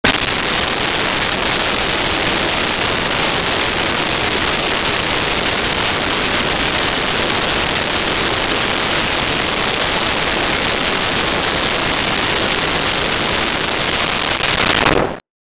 Concert Crowd
Concert Crowd.wav